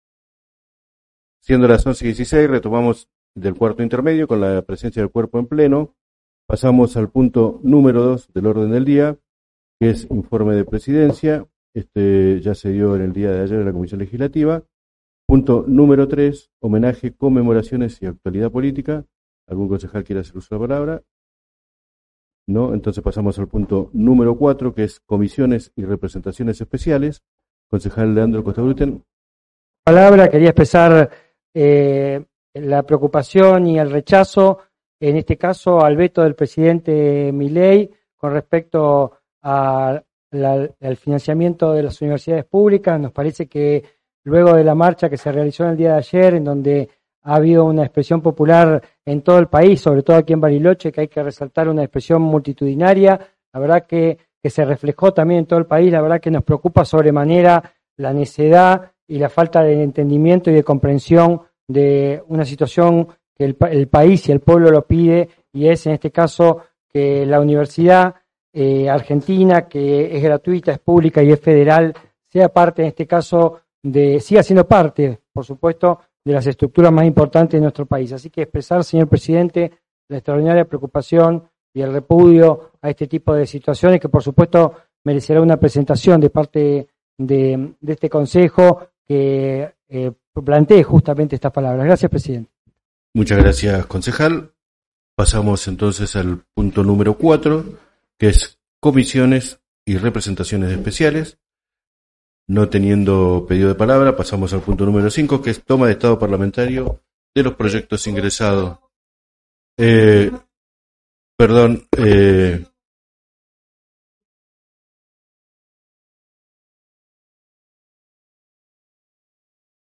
Actas de Sesión
Carácter de la Sesión: Ordinaria.
Se produce una interrupción fuera de micrófono.